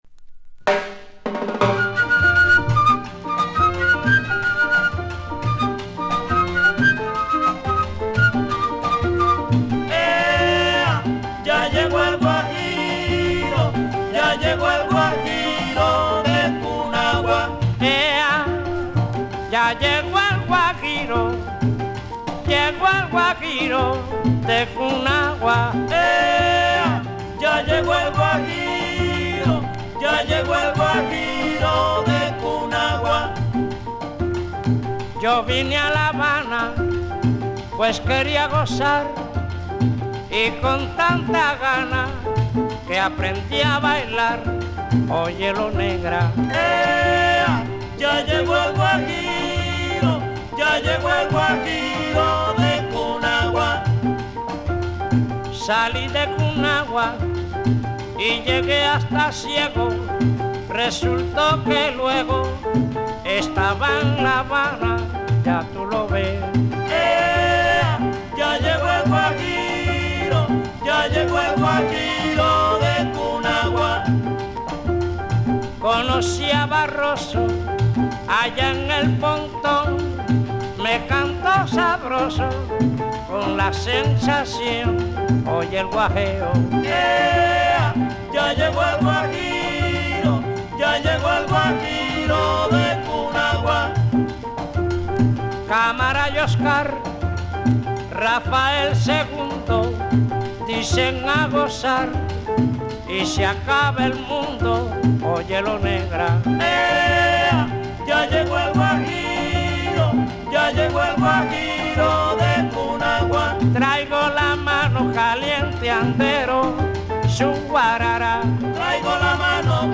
極上のSON MONTUNOが楽しめる